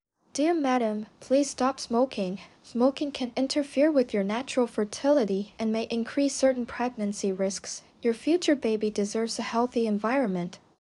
20-29 female.wav